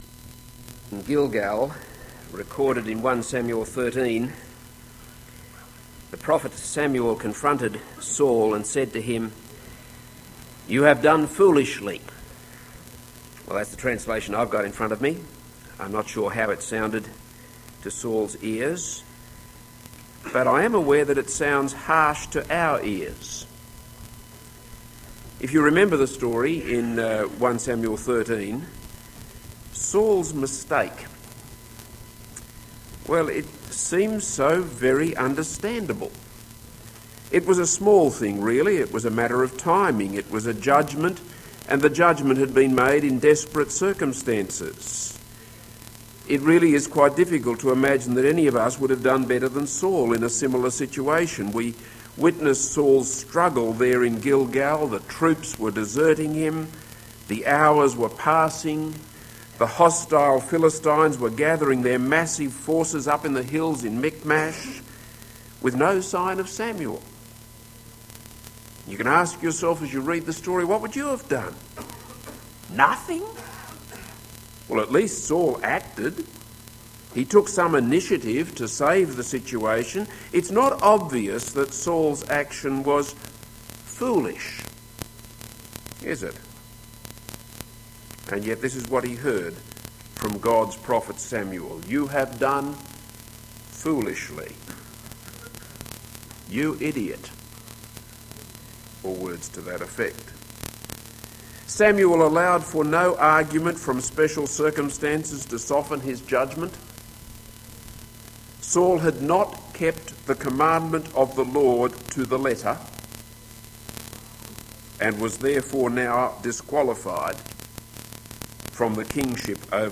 This is a sermon on 1 Samuel 14:24-52.